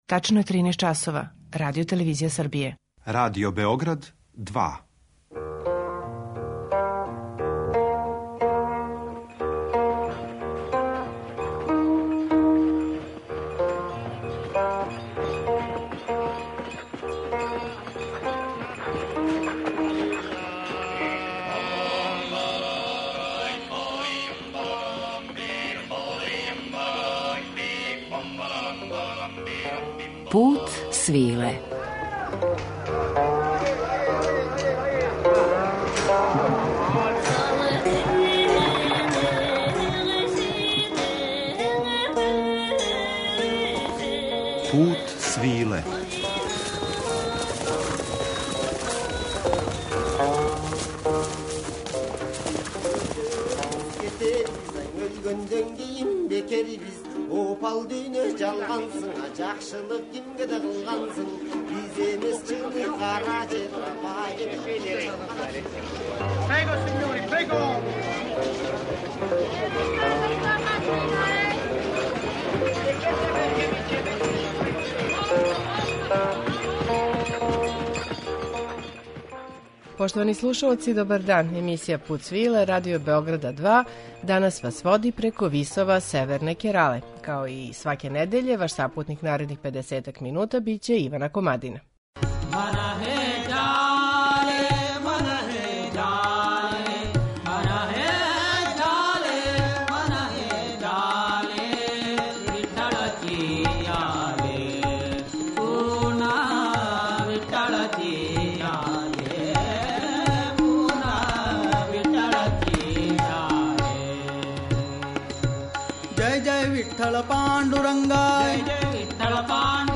Обилазимо северну Кералу, у друштву уметника који негују карнатичку музику традицију